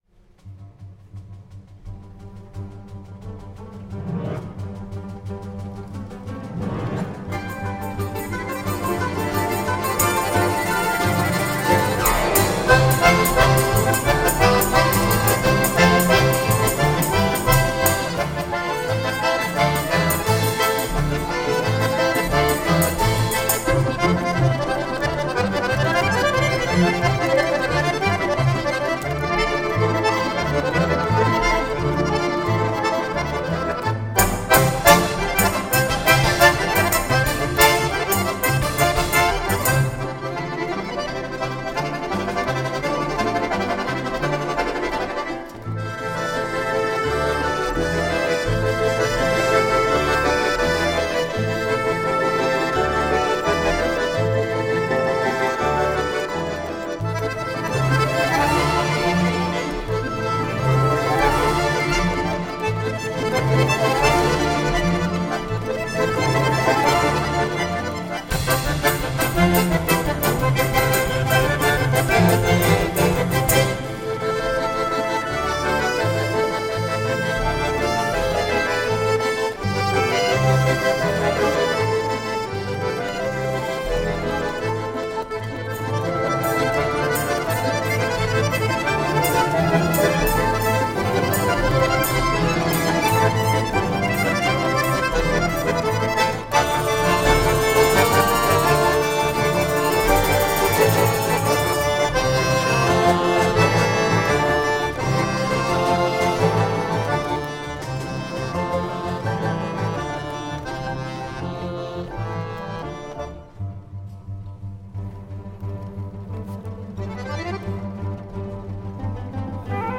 Произведение основано на народных песнях Орловской области: